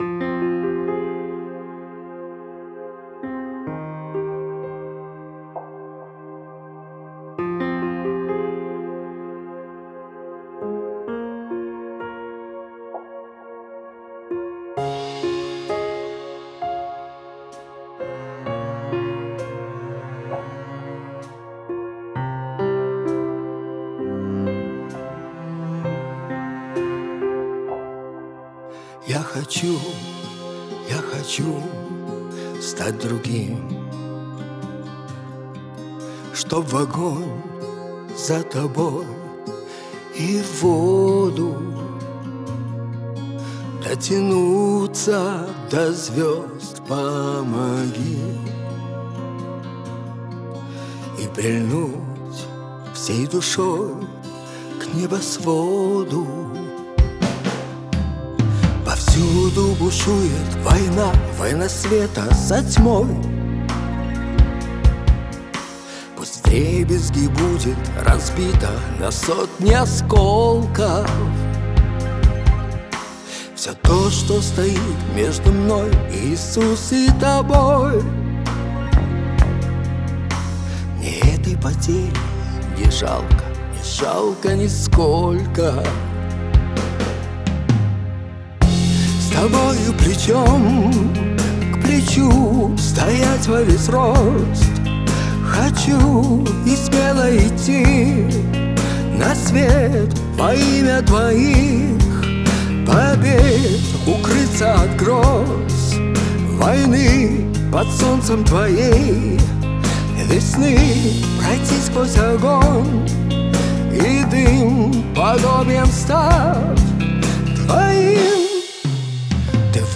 47 просмотров 99 прослушиваний 7 скачиваний BPM: 65